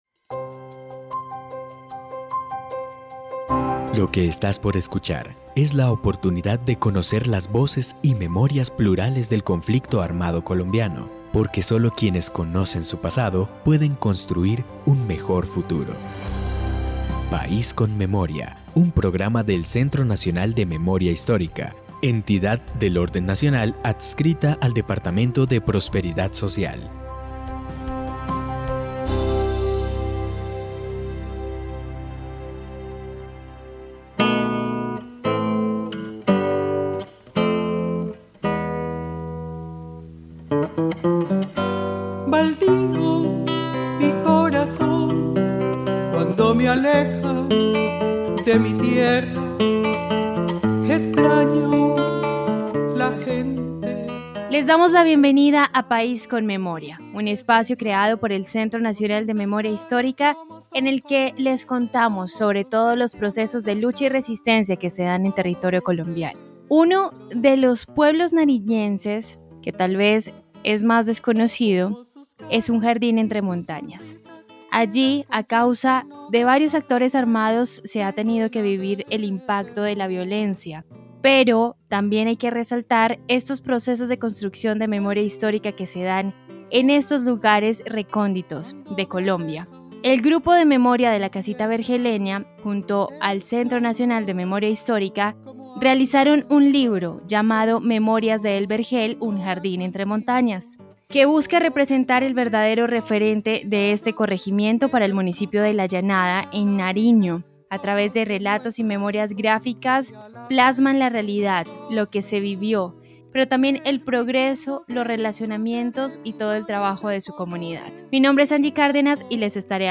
Este libro cuenta la historia de su territorio a través de relatos, y memorias gráficas que plasman la realidad que se vivió, el progreso, los relacionamientos, y el impacto del conflicto armado para su población. En País con Memoria hablamos con tres personas que participaron de este proceso.